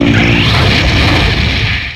Audio / SE / Cries / VOLCARONA.ogg